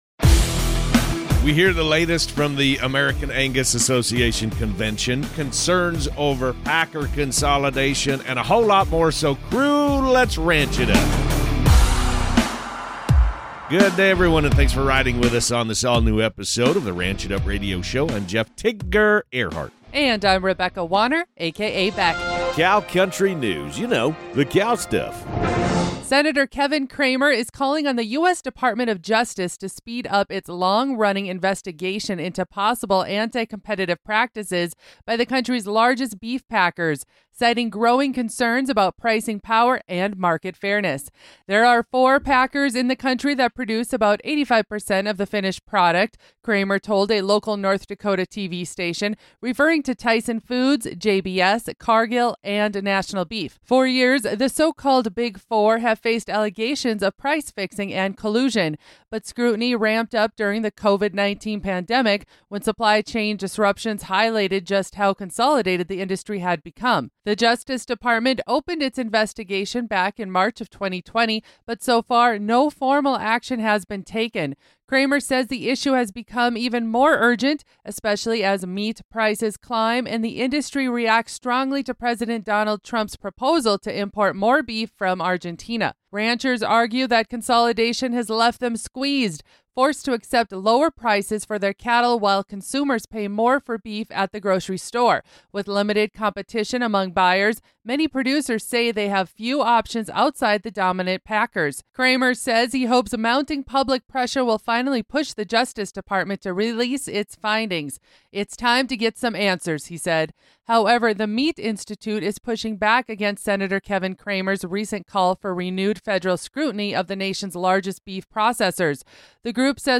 Hear directly from the American Angus Association Leadership Team on the NEW episode of The Ranch It Up Radio Show!